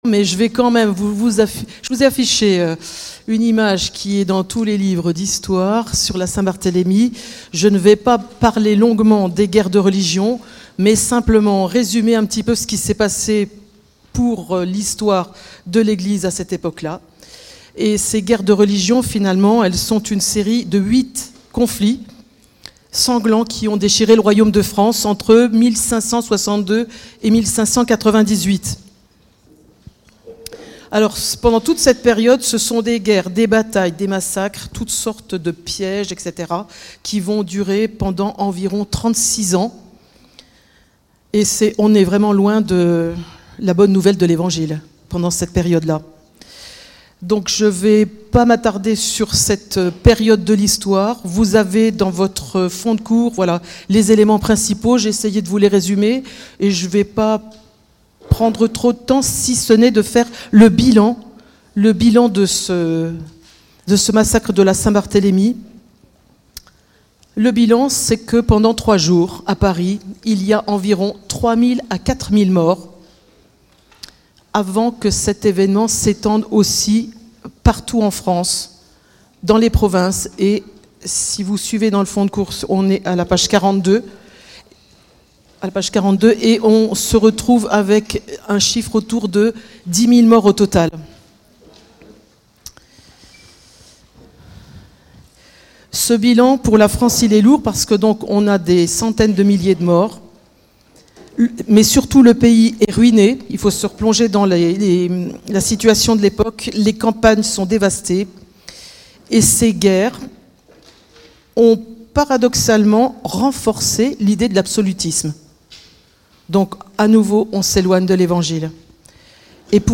Type De Service: ACBM CAMPUS